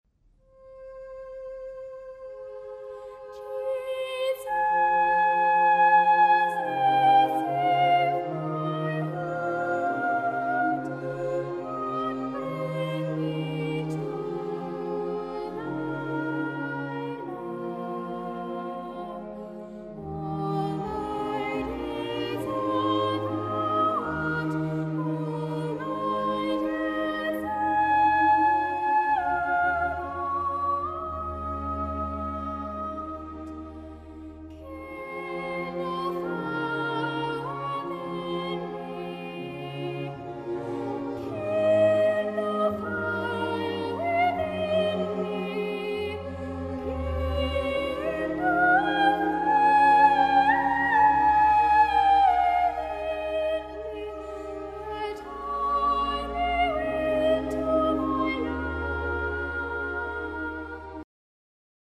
Unison